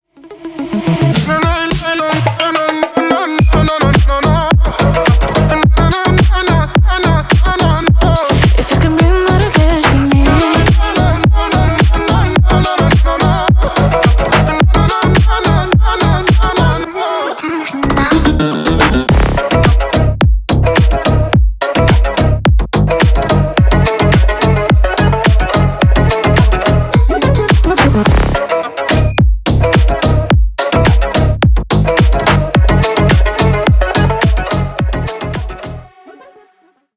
Ремикс # Танцевальные # восточные